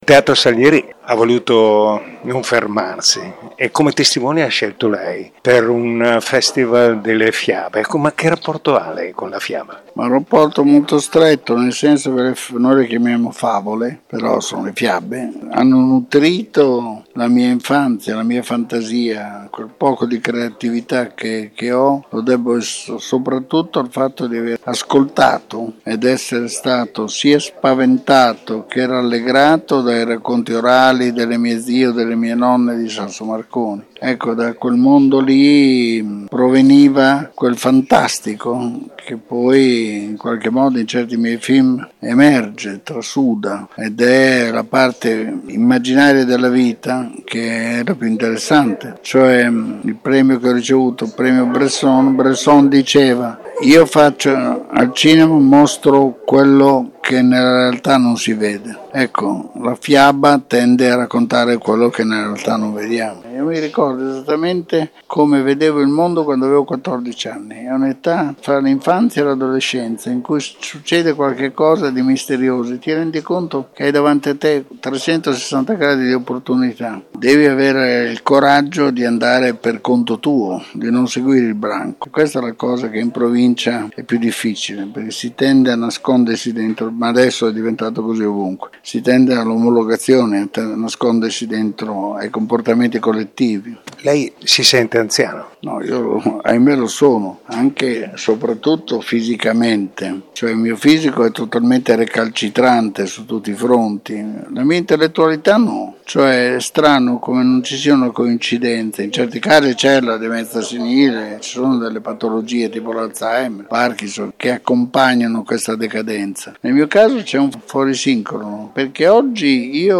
Tutto esaurito al Teatro Salieri per l’evento “Narratori si nasce”, ospite il grande Regista Pupi Avati, che pochi giorni fa alla Mostra del Cinema di Venezia ha ricevuto il prestigioso Premio Bresson.